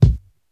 INSKICK09 -R.wav